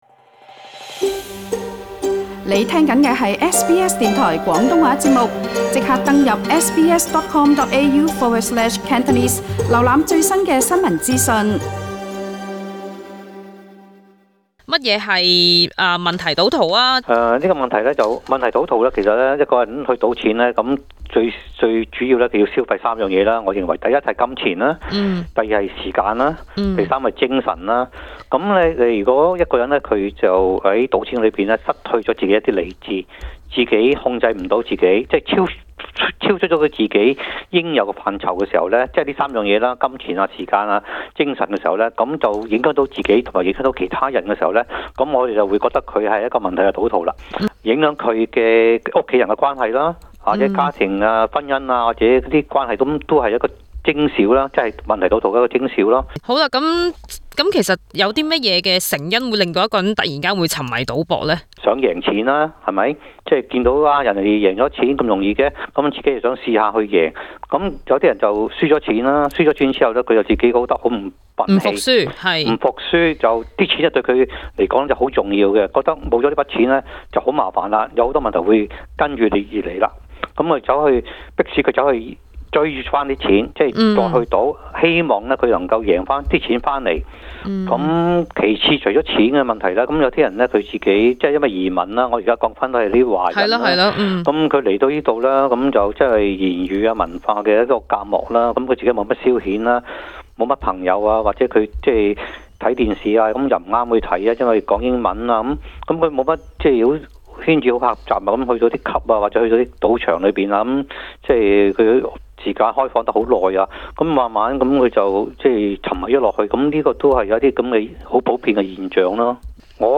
【社區專訪】輔導員教路：勸人戒賭勿用激將法